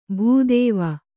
♪ bhū dēva